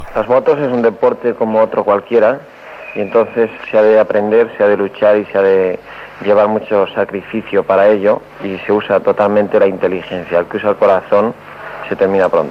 Declaracions del pilot Ángel Nieto al circuit de Sachsenring (Alemanya Democràtica), després de guanyar la cursa de 50 cc del Gran Premi d'Alemanya de l'Est de Motociclisme